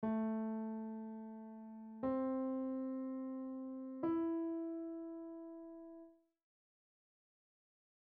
Click to listen to the three notes ... by listening only, can you find them on your piano and play them?
Piano Notes